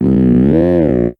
Catégorie:Cri Pokémon (Soleil et Lune) Catégorie:Cri de Chelours